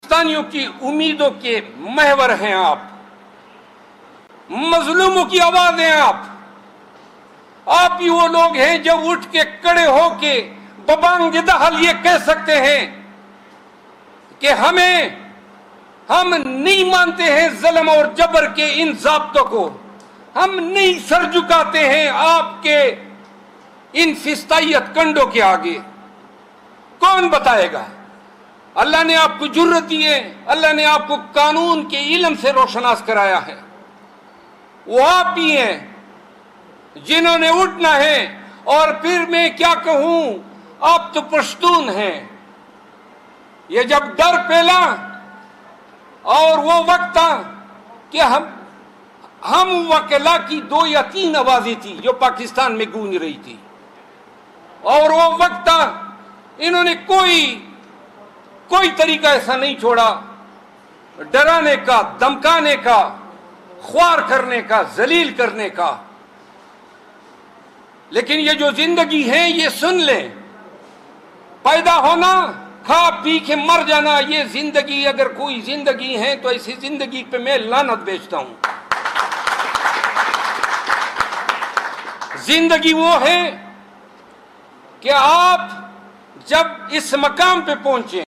sherafzalmarwat speech to lawyers